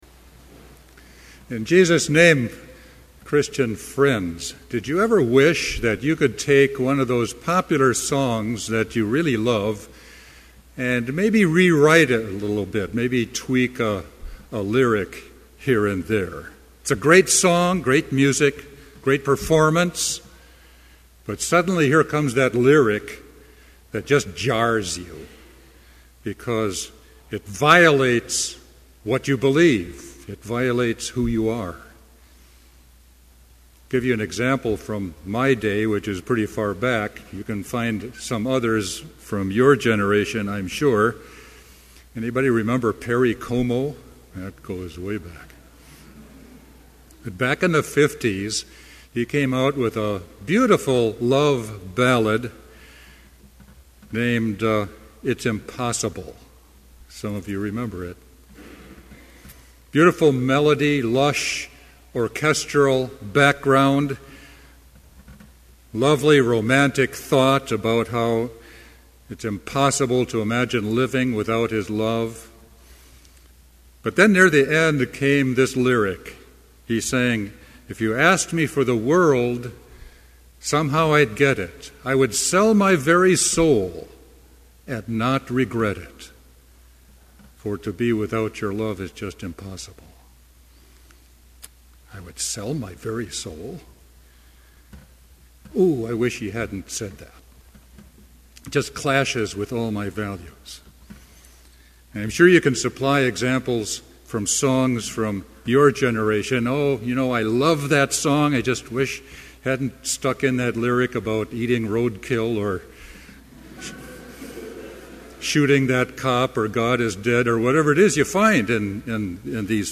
Complete service audio for Chapel - November 3, 2011